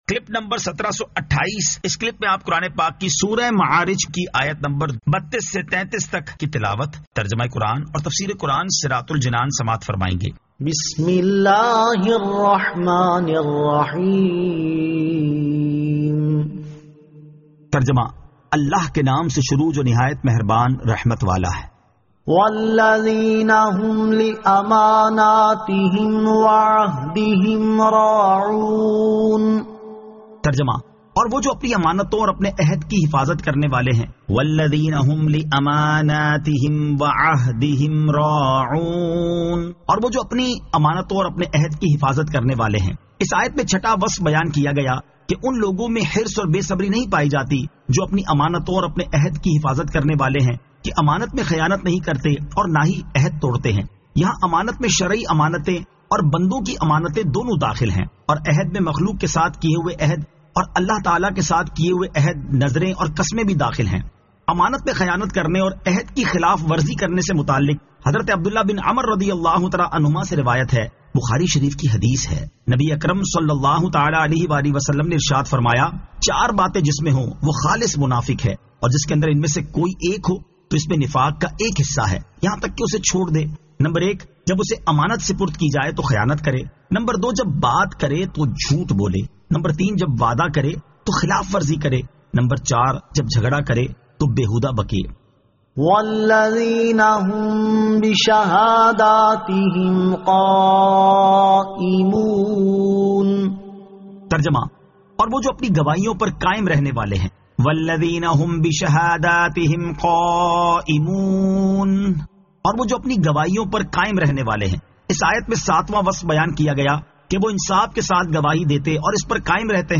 Surah Al-Ma'arij 32 To 33 Tilawat , Tarjama , Tafseer